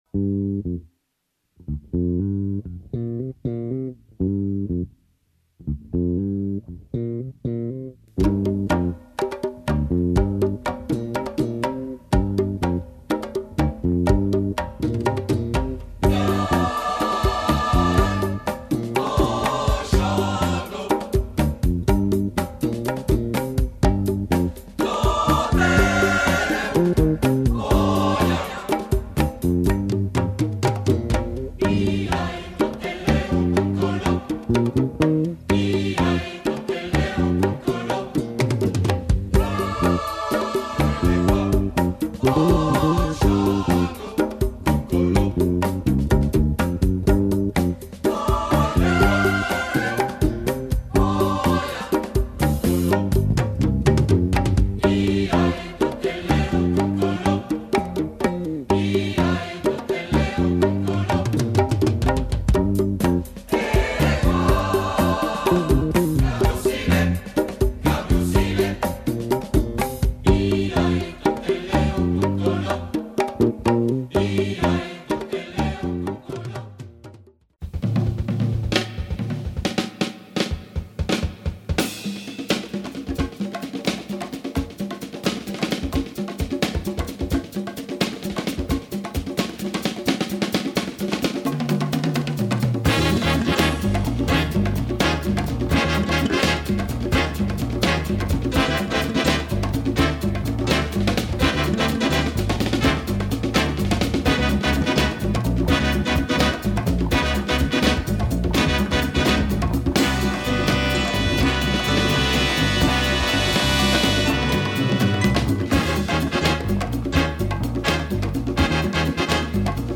afro latin / salsa / caribbean jazz album